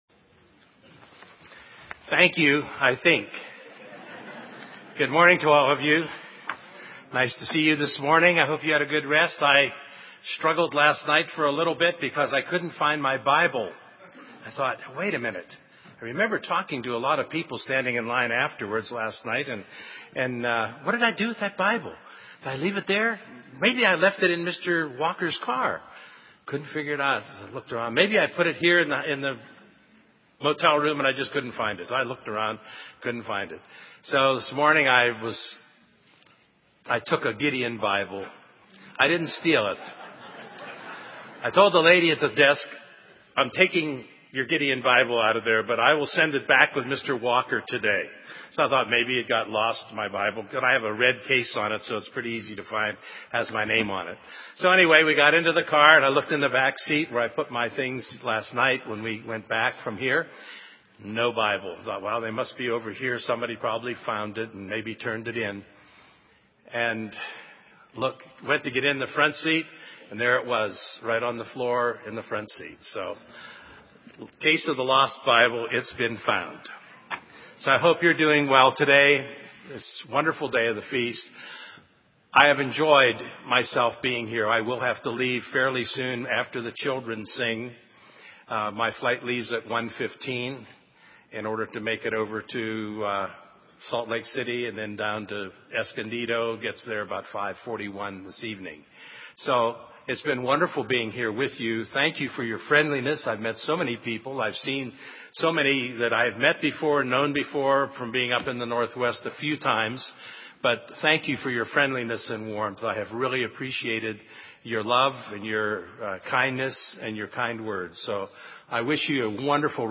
This sermon was given at the Bend, Oregon 2011 Feast site.